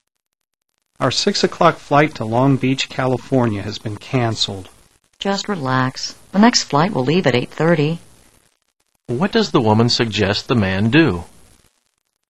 What does the woman suggest the man do?